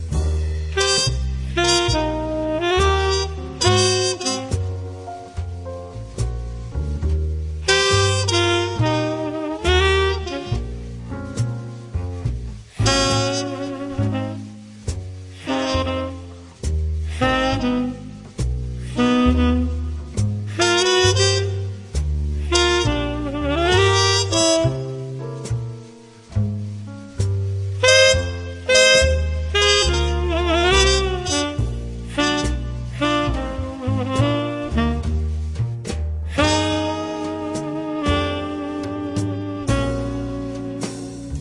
Recorded at the Red Gables Studio, September 26th 2006
muted trumpet